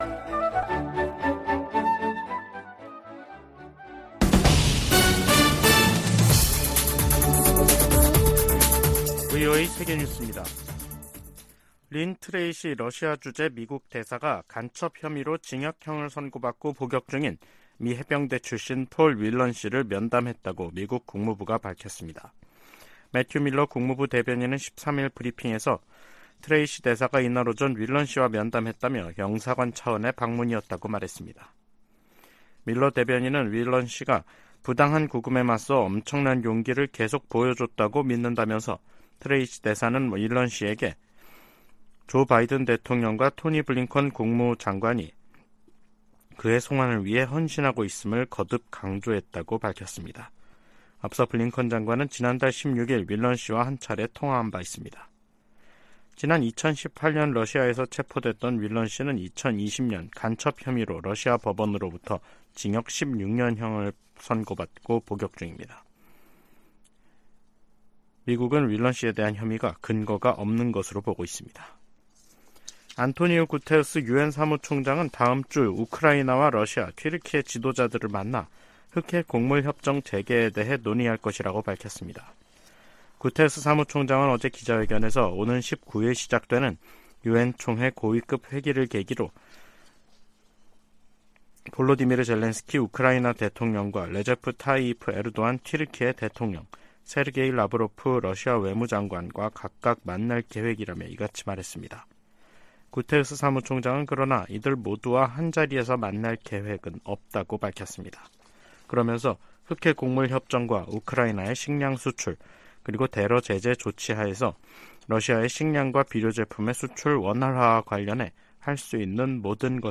VOA 한국어 간판 뉴스 프로그램 '뉴스 투데이', 2023년 9월 14일 3부 방송입니다. 러시아를 방문 중인 김정은 국무위원장이 푸틴 대통령의 방북을 초청한 것으로 북한 관영 매체가 보도했습니다.